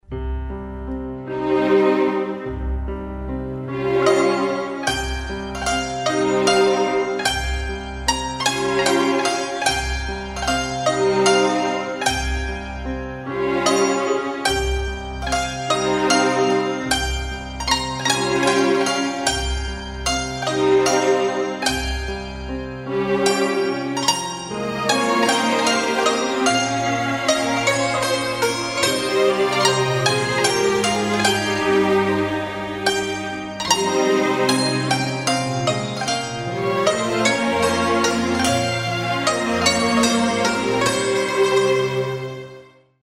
رینگتون بی کلام با ملودی عاشقانه